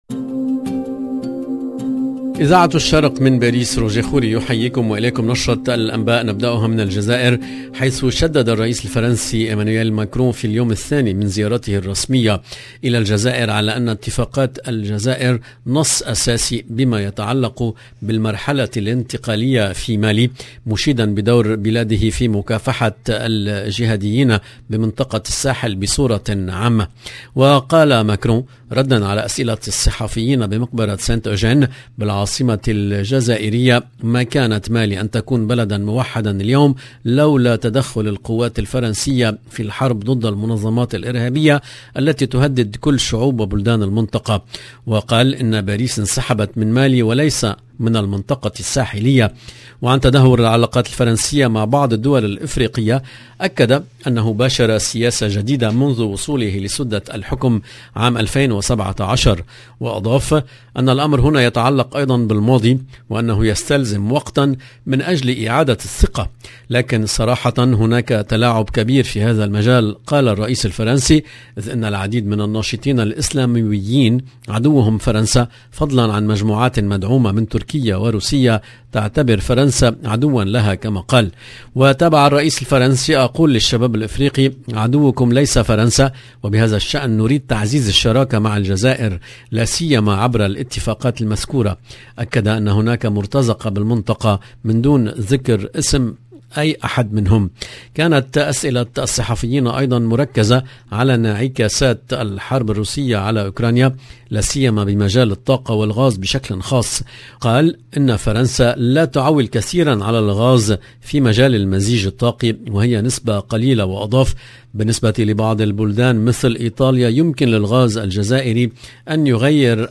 EDITION DU JOURNAL DU SOIR EN LANGUE ARABE DU 26/8/2022